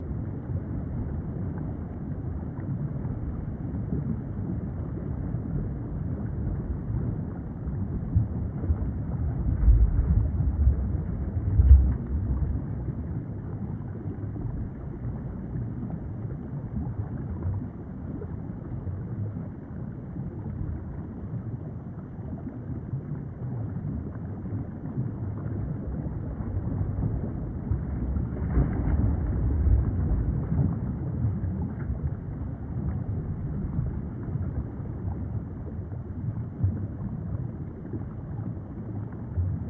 Underwater Ambience | Sneak On The Lot
Deep Water Ambience, Loop, Calm To Medium Active